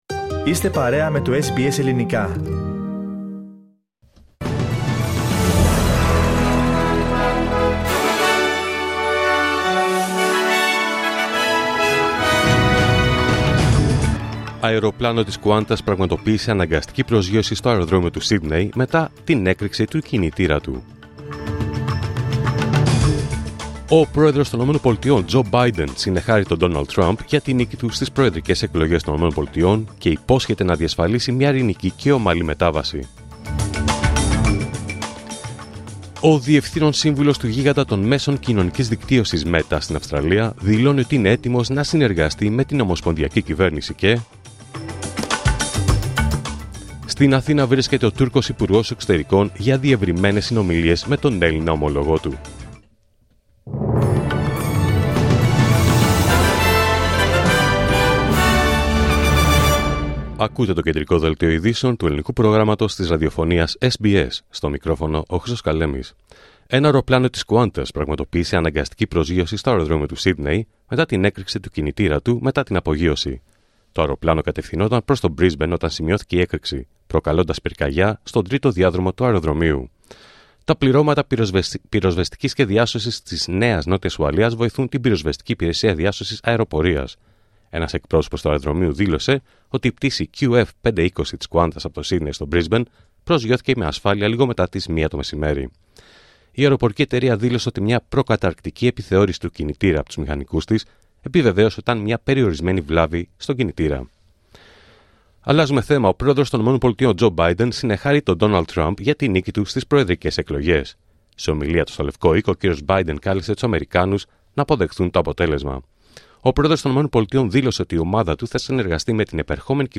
Δελτίο Ειδήσεων Παρασκευή 8 Νοέμβριου 2024